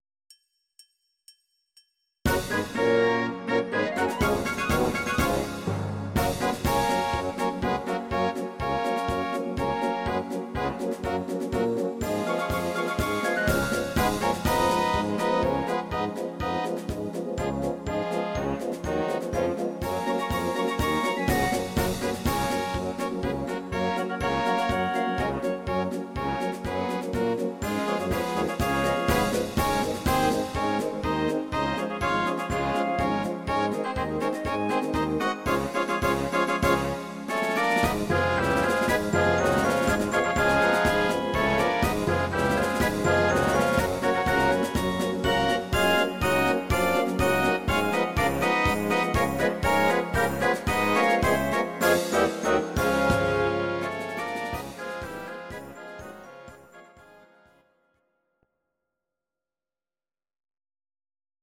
These are MP3 versions of our MIDI file catalogue.
Please note: no vocals and no karaoke included.
instr. Orchester